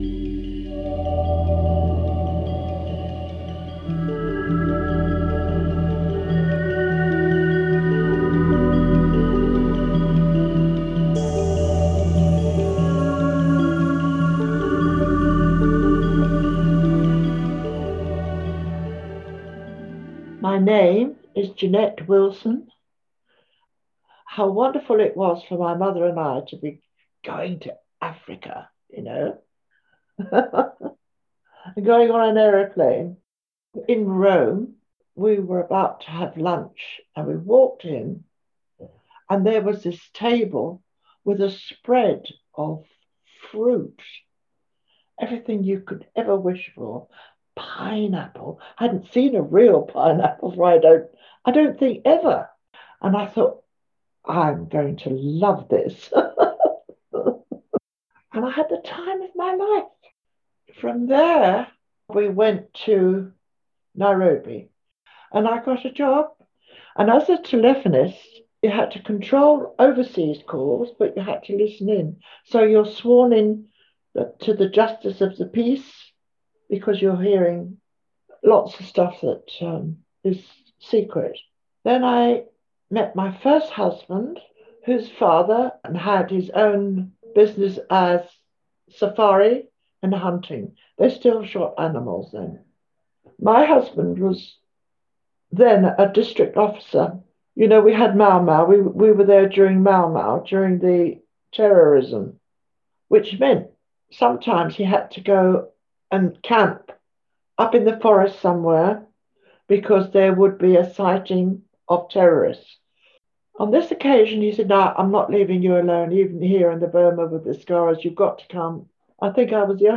interviewed residents and staff from Somerset Care and local elders from Yeovil